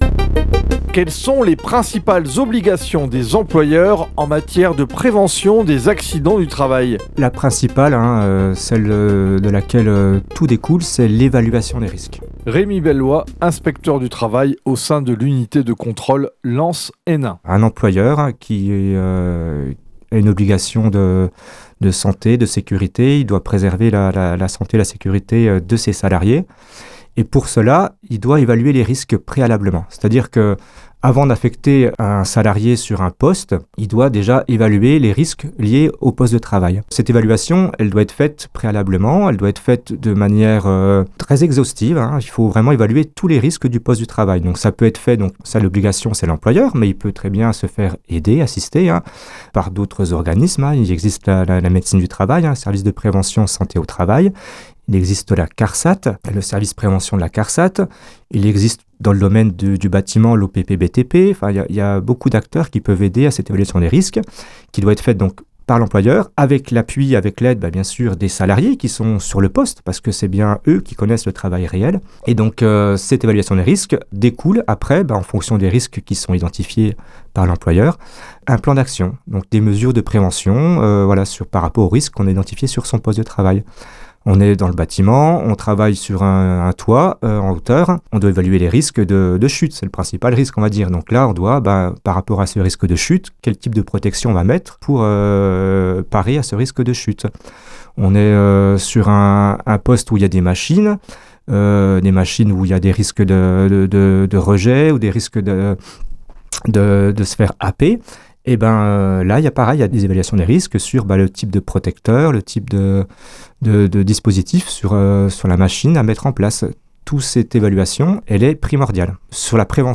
8 spots radio diffusés à l’automne 2025 :